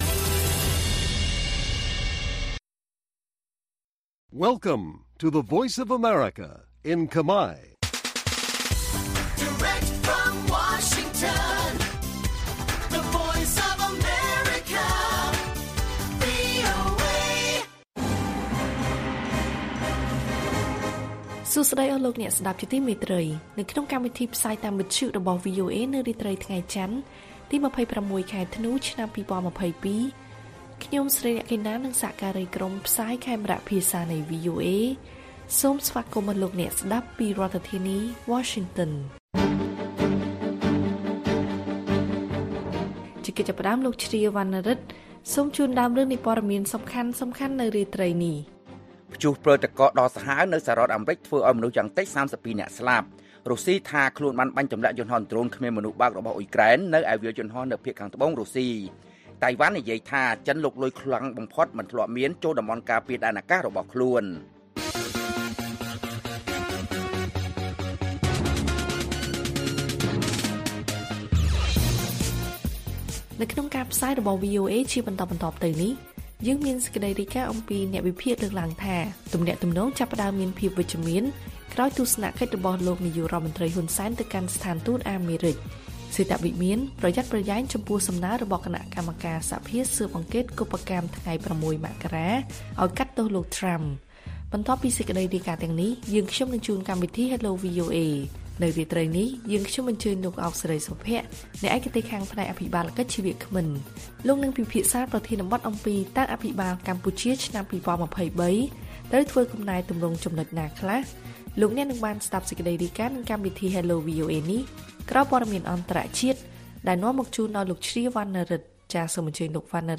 ព័ត៌មានពេលរាត្រី ២៦ ធ្នូ៖ អ្នកវិភាគថា ទំនាក់ទំនងចាប់ផ្តើមវិជ្ជមាន ក្រោយទស្សនកិច្ចរបស់លោក ហ៊ុន សែន ទៅស្ថានទូតអាមេរិក